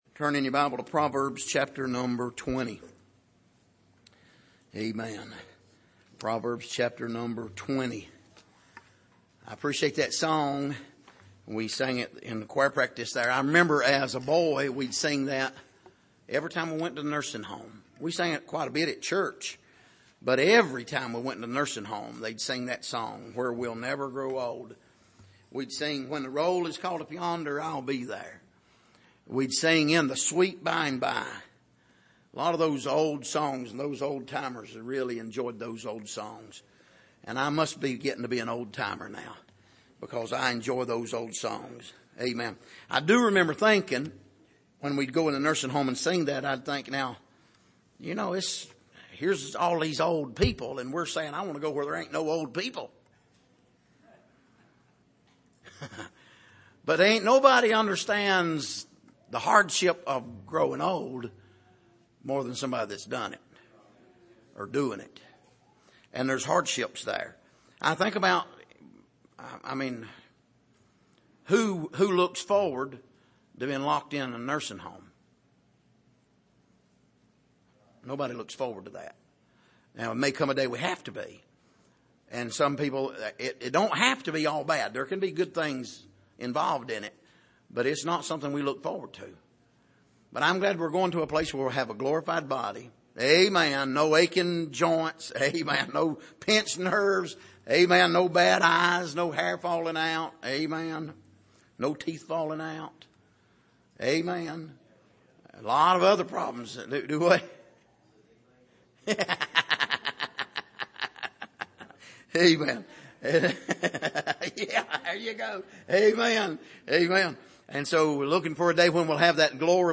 Passage: Proverbs 20:8-15 Service: Sunday Evening